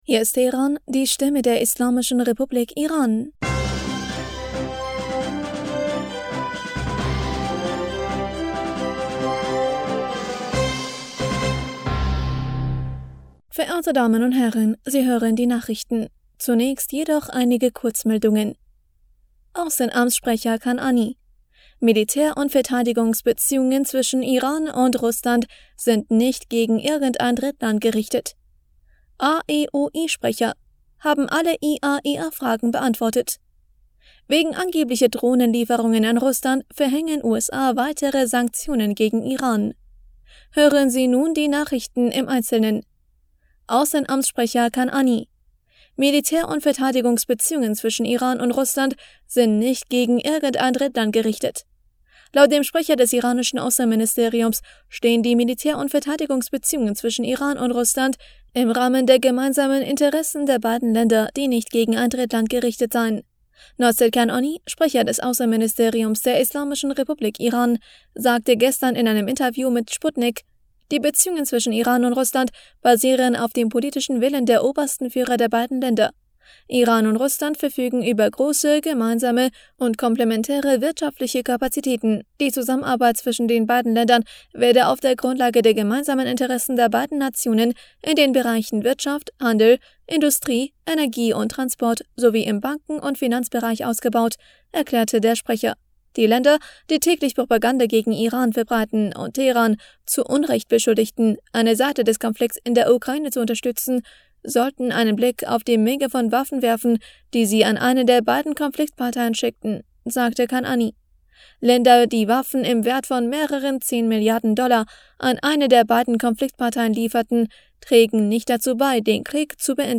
Nachrichten vom 25. Februar 2023